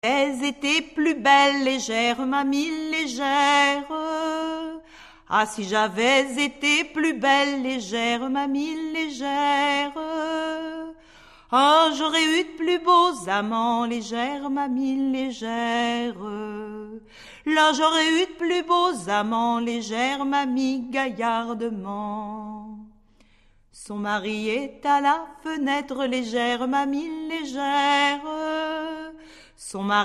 Musique : Traditionnel
Origine : Bretagne